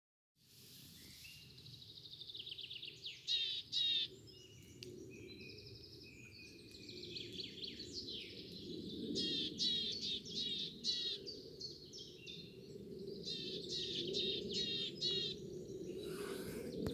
Willow Tit, Poecile montanus
StatusAgitated behaviour or anxiety calls from adults